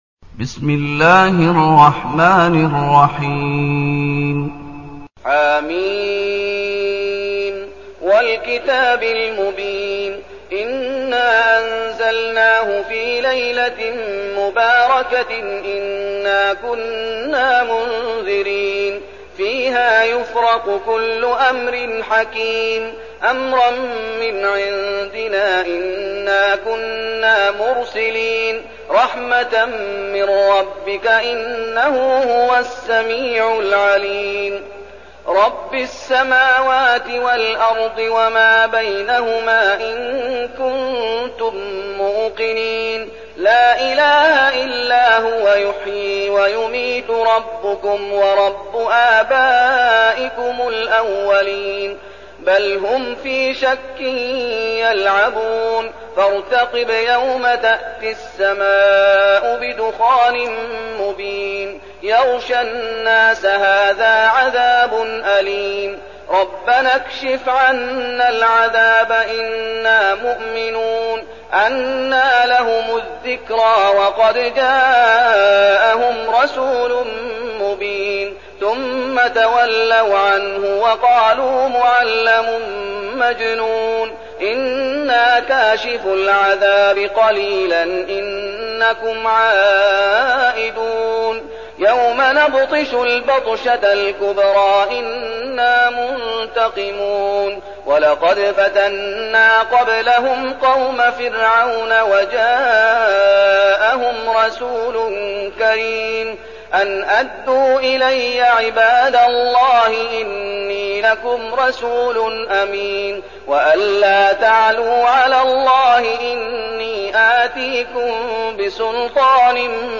المكان: المسجد النبوي الشيخ: فضيلة الشيخ محمد أيوب فضيلة الشيخ محمد أيوب الدخان